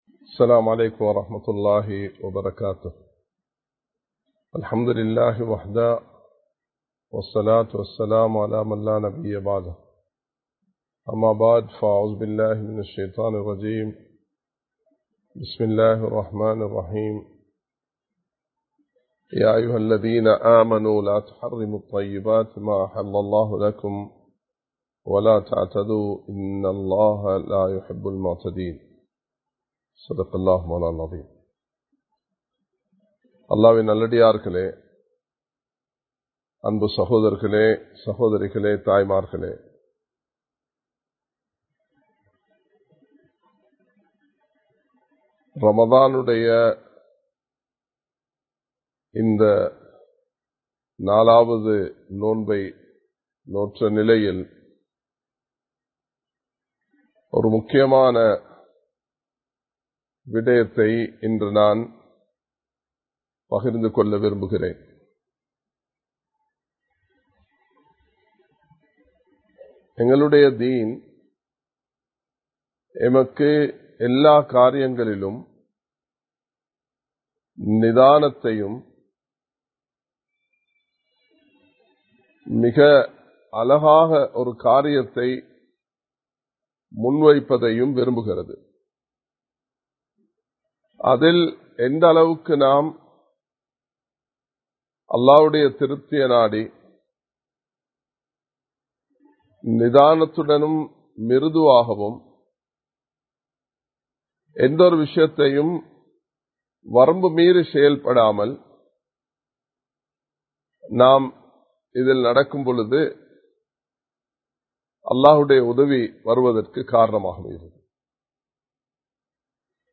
Live Stream